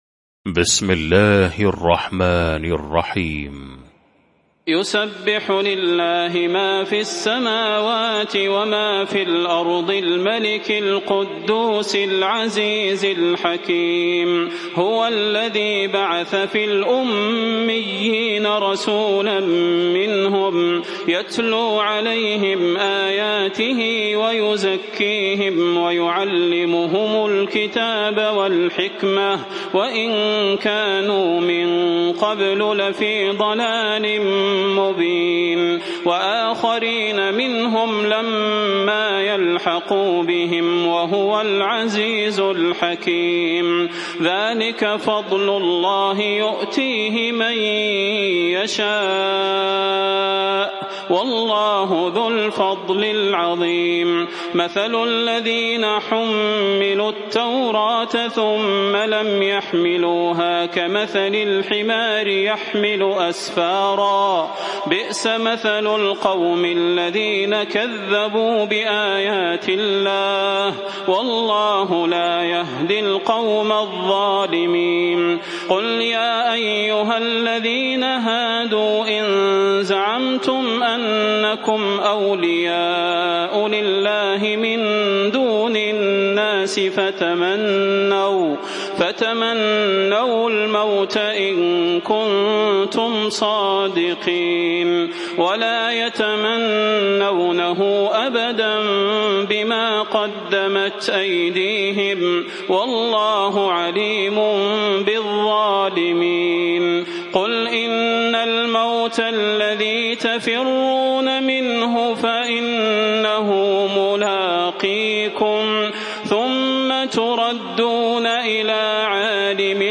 المكان: المسجد النبوي الشيخ: فضيلة الشيخ د. صلاح بن محمد البدير فضيلة الشيخ د. صلاح بن محمد البدير الجمعة